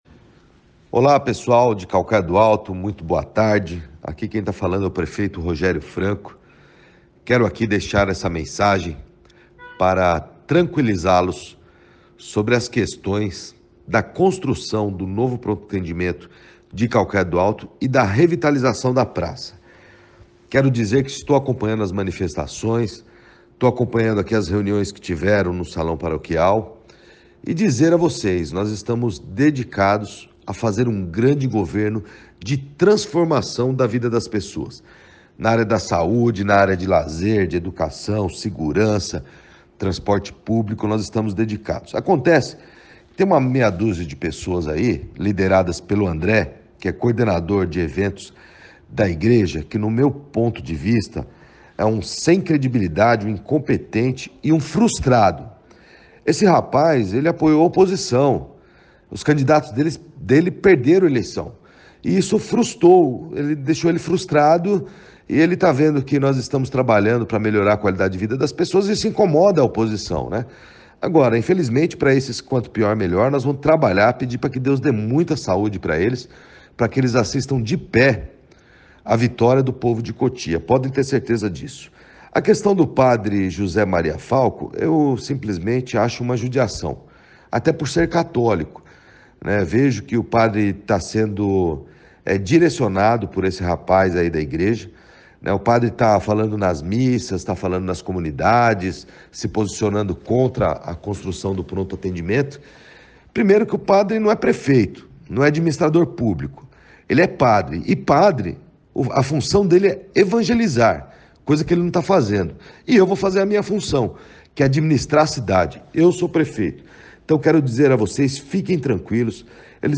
Antes da decisão ser proferida pela justiça na tarde desta quarta-feira (26), o prefeito Rogério Franco fez circular um áudio aos moradores de Caucaia em que comenta a polêmica e classificou o movimento como politiqueiro.
ROGERIO-FRANCO.m4a